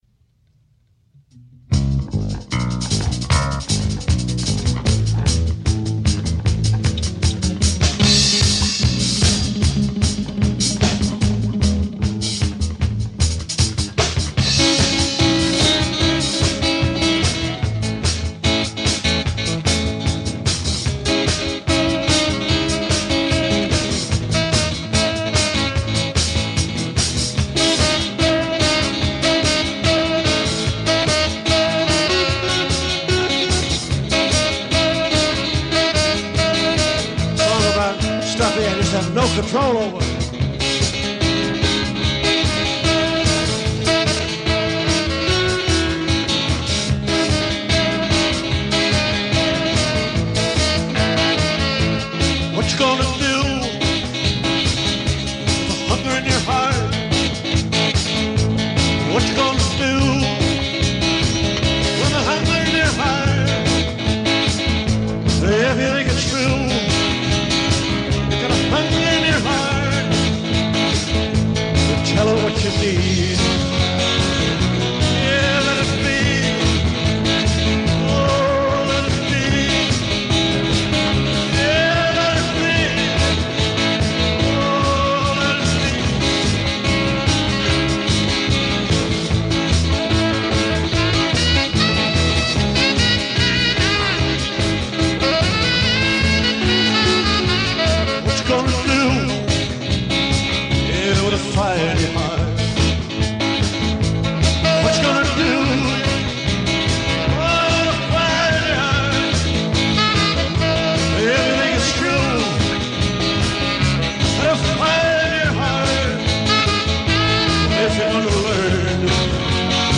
a great wailing sax
bass
drums
So here is what I think is an unreleased cut.